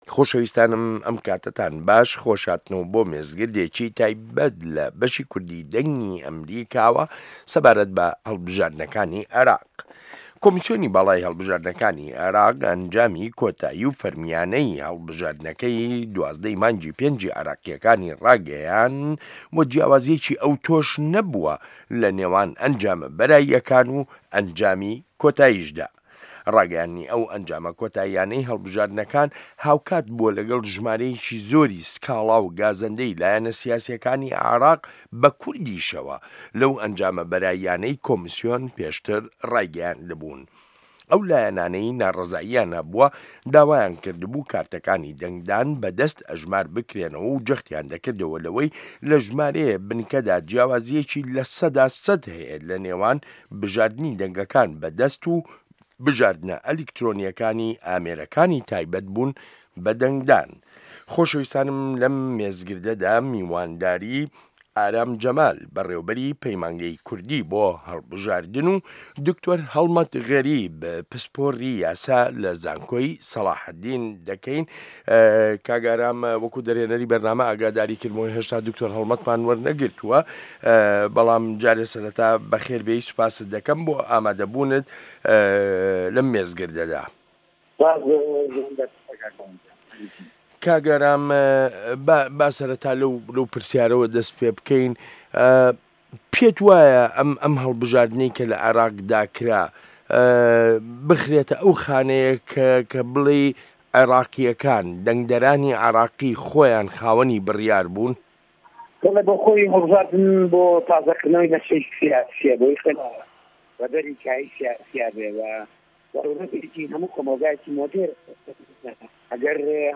مێزگرد: ڕەوایی ئەنجامەکانی هەڵبژاردنی عێراق